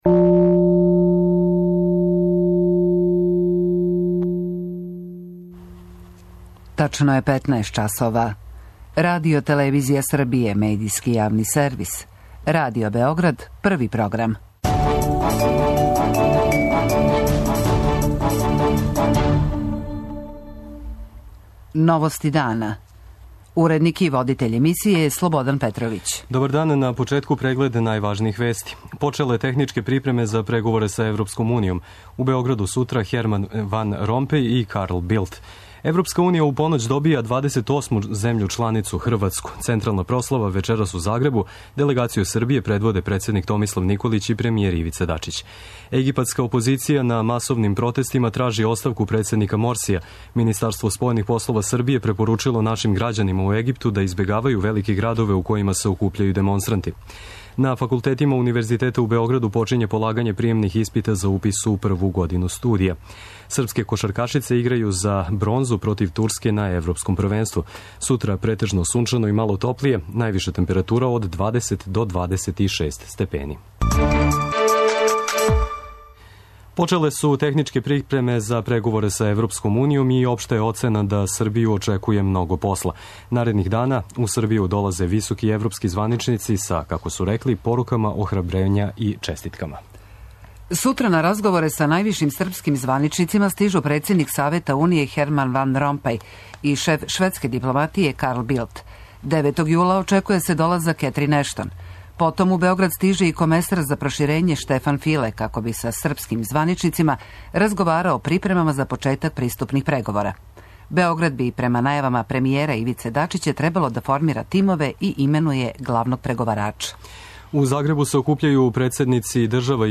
Радио Београд 1, 15.00